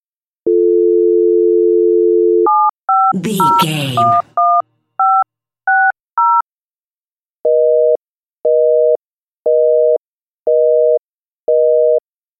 Telephone tone dial 8 numbers busy
Sound Effects
phone